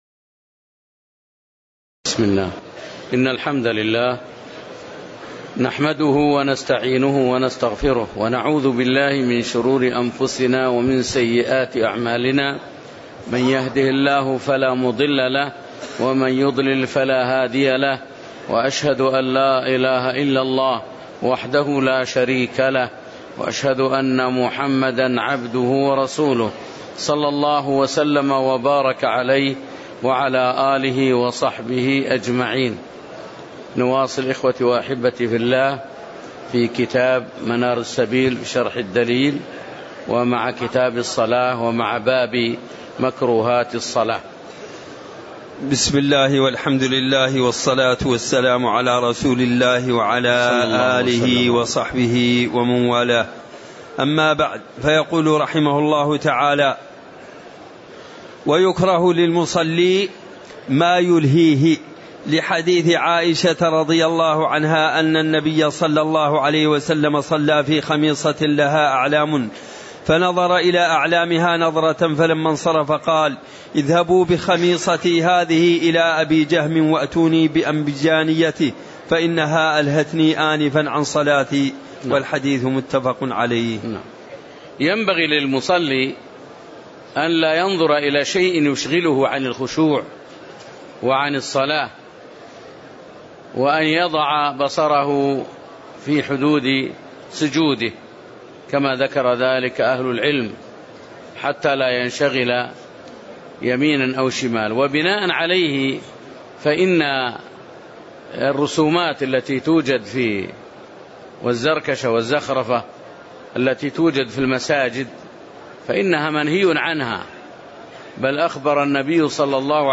تاريخ النشر ١٨ ذو الحجة ١٤٣٨ هـ المكان: المسجد النبوي الشيخ